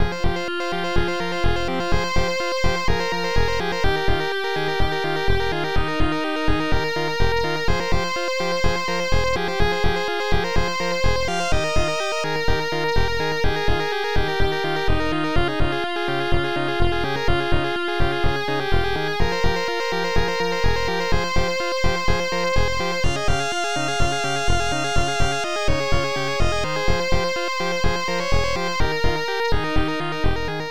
( .mp3 ) < prev next > Protracker Module | 1992-04-27 | 6.3 KB | 2 channels | 44,100 sample rate | 30 seconds Title so what... Type Protracker and family Tracker Noisetracker M.K. Tracks 4 Samples 31 Patterns 5 Instruments ST-69:bluz ST-05:italosnare